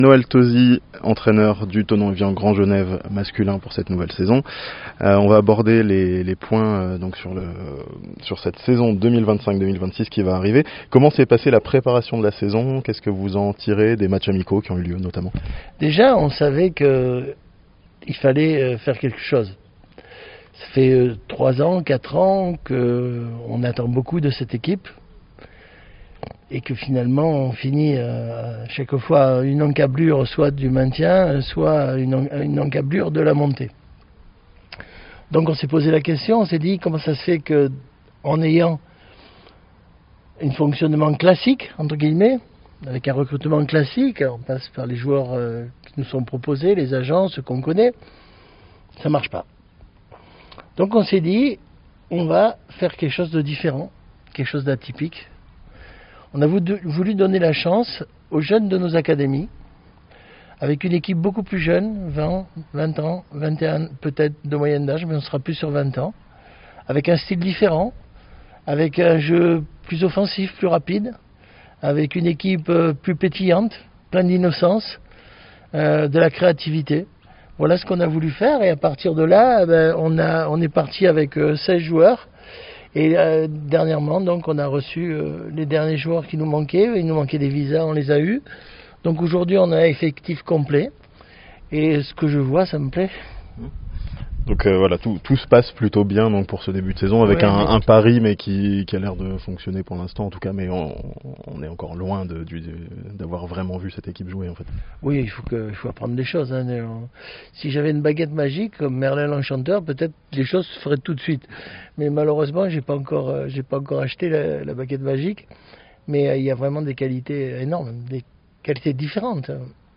Thonon Evian Grand Genève FC mise sur la jeunesse pour la nouvelle saison (interview)